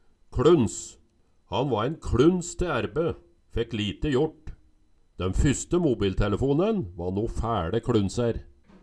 Høyr på uttala Ordklasse: Substantiv hankjønn Kategori: Karakteristikk Attende til søk